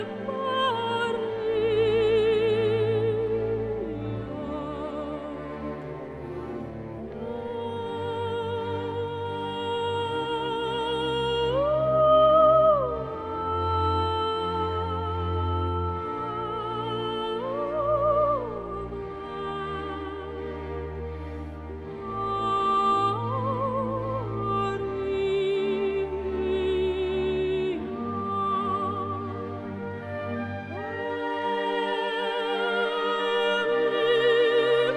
Classical Crossover Classical
Жанр: Классика